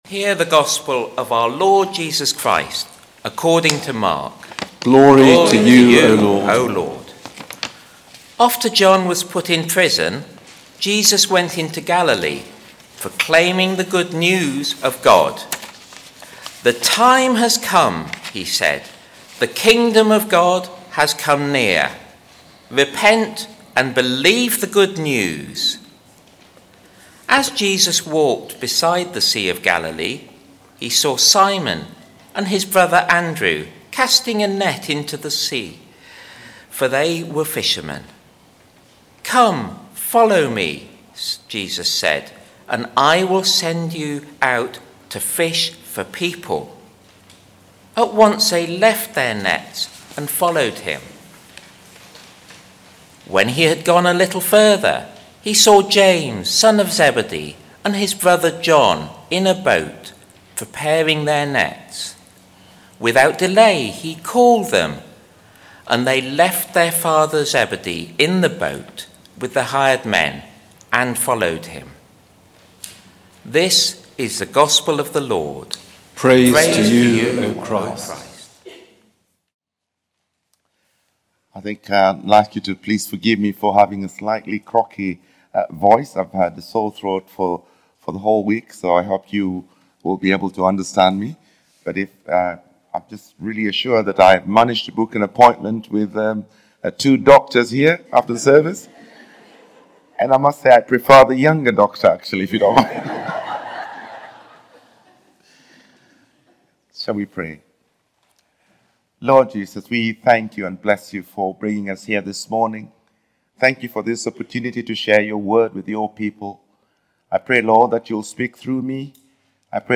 Speaker: The Rt Revd Tim Wambunya Passage: Acts 2: 36-47, Mark 1: 14-20
Confirmation Sermon